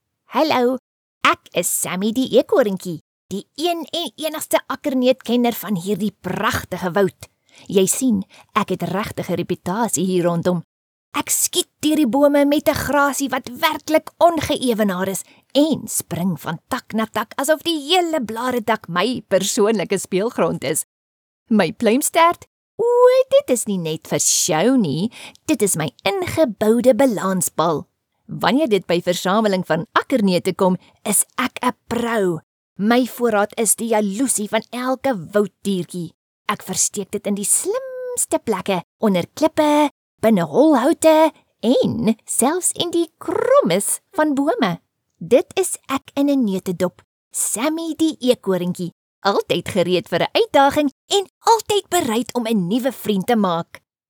Animation
Meine Stimme kann zugänglich und freundlich, bestimmend, warm und glaubwürdig oder auch schrullig und lebhaft sein.
Schallisolierter Raum
AnsagerExperteErzählerPrinzessinSnobErzählerHexe